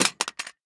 46265b6fcc Divergent / mods / Soundscape Overhaul / gamedata / sounds / material / small-weapon / collide / hithard02hl.ogg 9.7 KiB (Stored with Git LFS) Raw History
hithard02hl.ogg